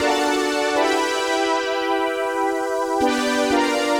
Index of /musicradar/80s-heat-samples/120bpm
AM_VictorPad_120-E.wav